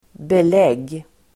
Uttal: [bel'eg:]